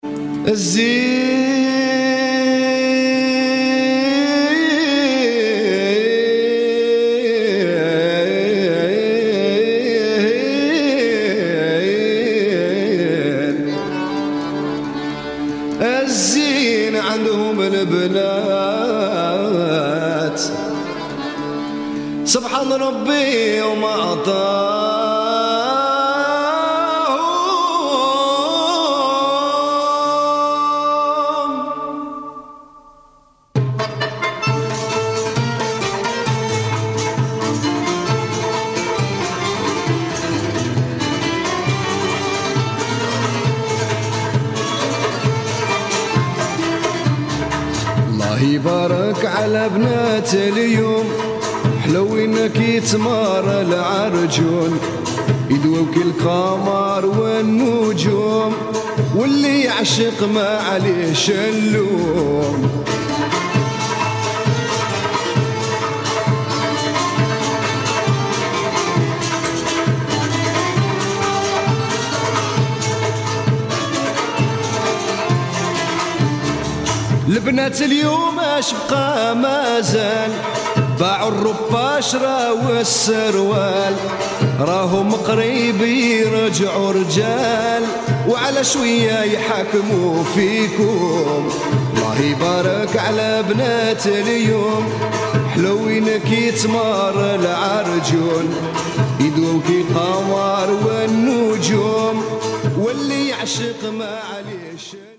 Jewish music from Algeria.